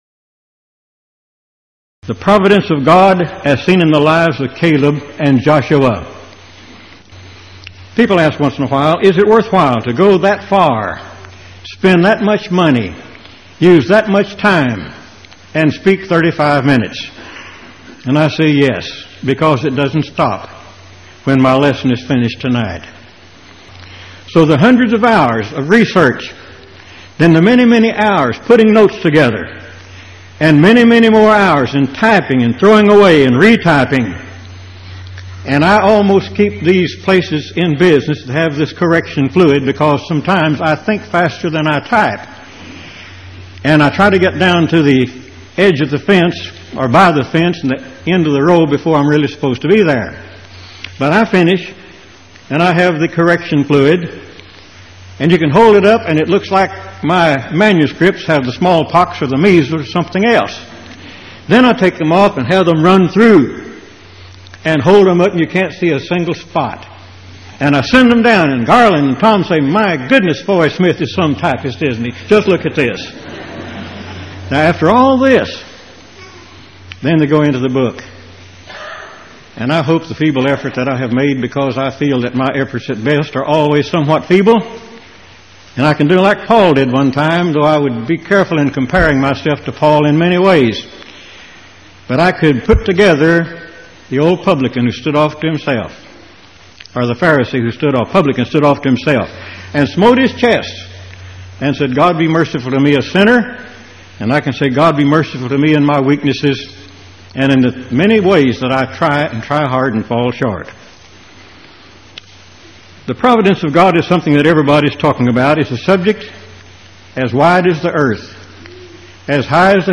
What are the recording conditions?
Event: 1989 Power Lectures